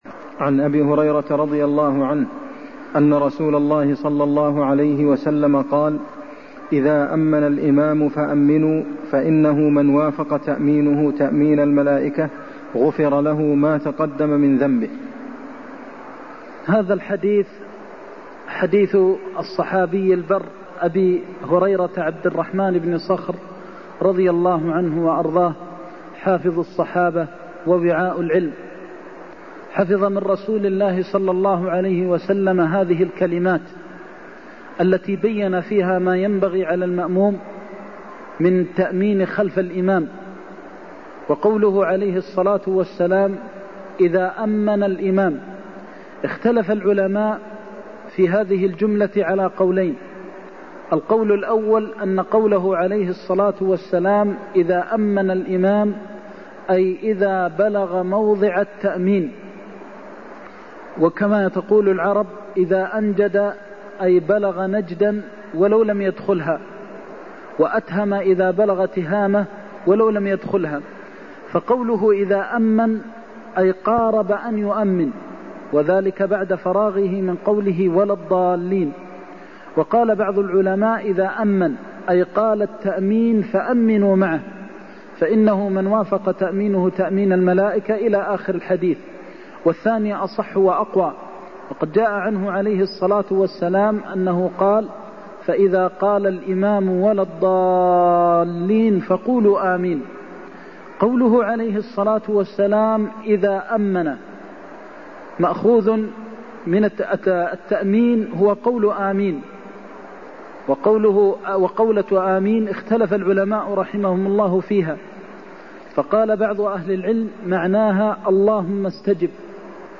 المكان: المسجد النبوي الشيخ: فضيلة الشيخ د. محمد بن محمد المختار فضيلة الشيخ د. محمد بن محمد المختار إذا أمن الإمام فأمنوا (75)